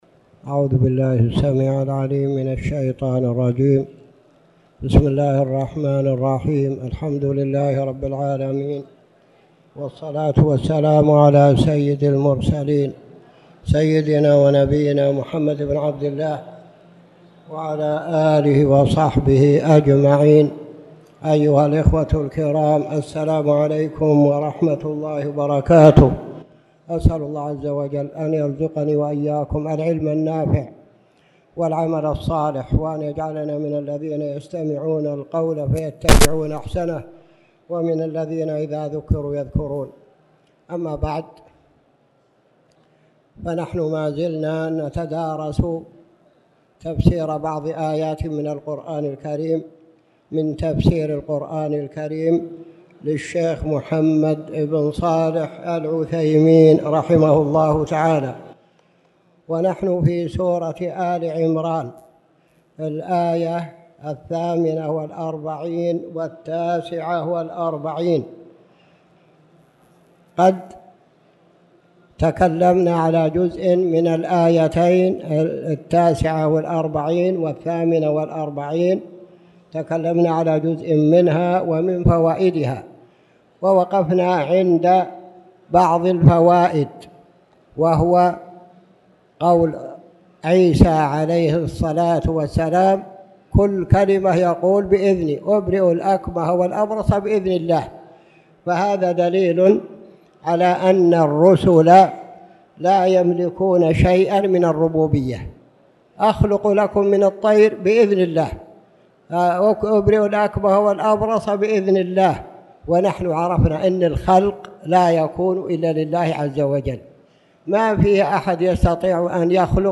تاريخ النشر ٢٤ جمادى الأولى ١٤٣٨ هـ المكان: المسجد الحرام الشيخ